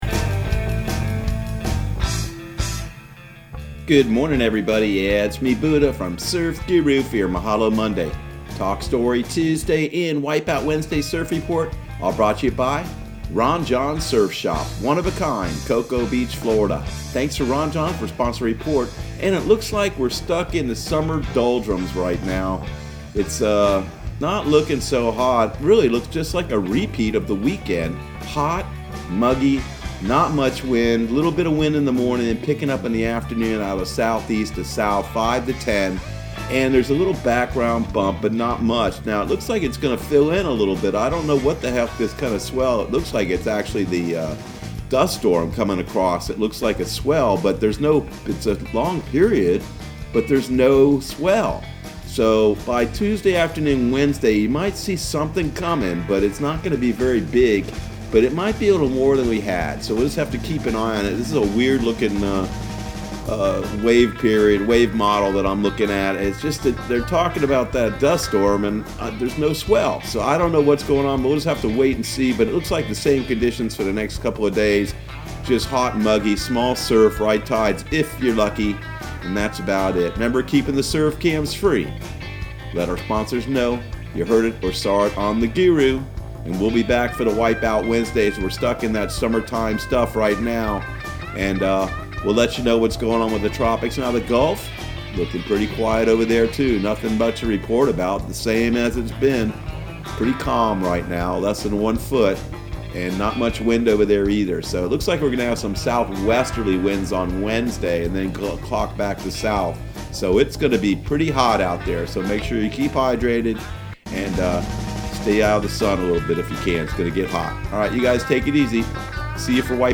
Surf Guru Surf Report and Forecast 06/22/2020 Audio surf report and surf forecast on June 22 for Central Florida and the Southeast.